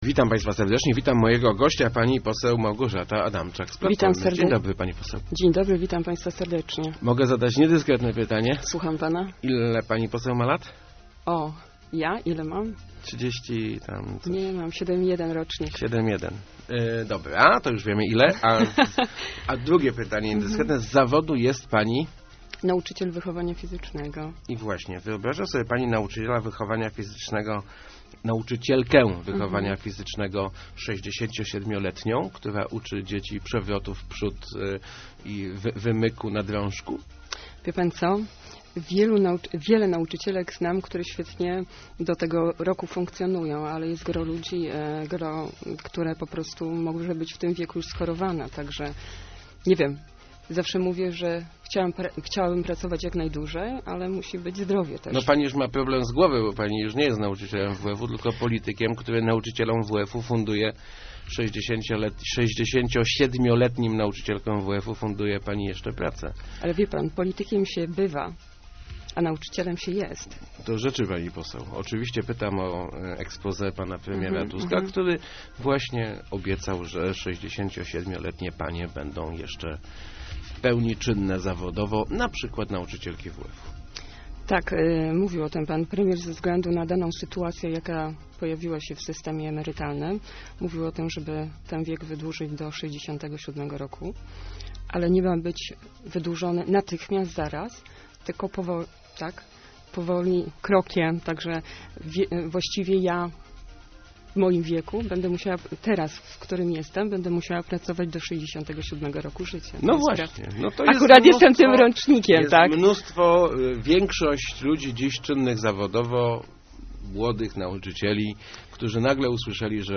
madamczak80.jpgChcemy, żeby ludziom żyło się lepiej, ale mamy kryzys - mówiła w Rozmowach Elki posłanka PO Małgorzata Adamczak. Zastrzegła też, że plany co do na przykład podwyższenia wieku emerytalnego będą jeszcze konsultowane.